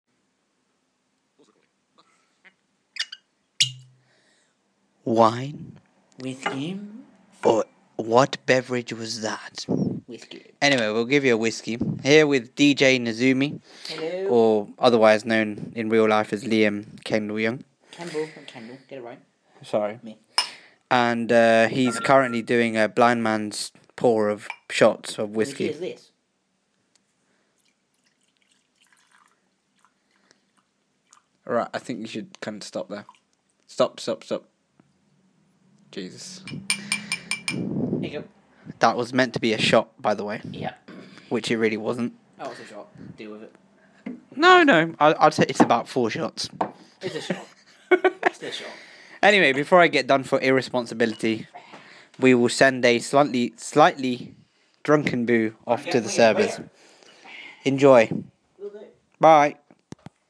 fun improvisations
Jingle bells excuse the mistakes